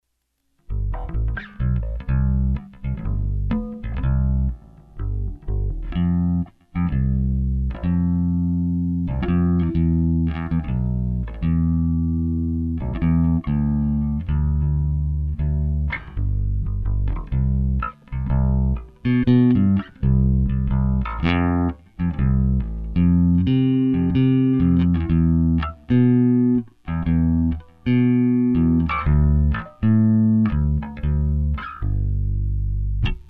的調整，經過設定之後，如圖 3-5，平均的電平在 -17 dBFS ~ -13 dBFS 。
圖 3-5 有壓縮動作的Bass 訊號波形。